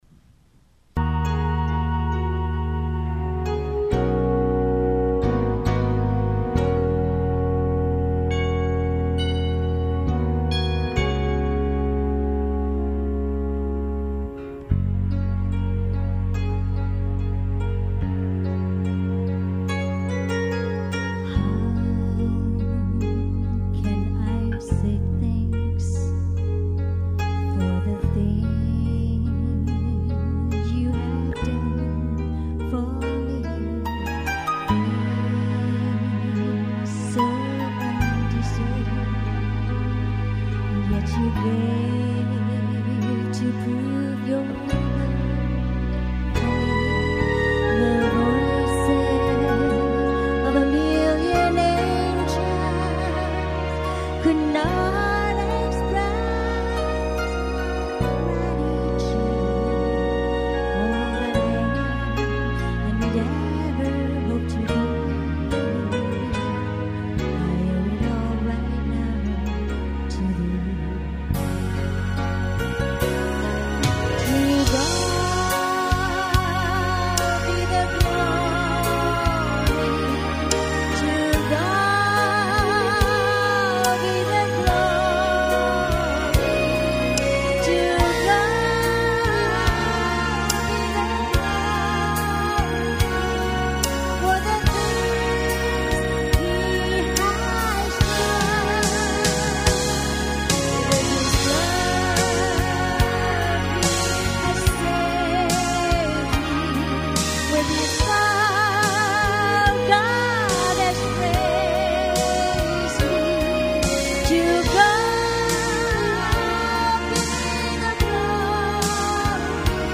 Music/Nhạc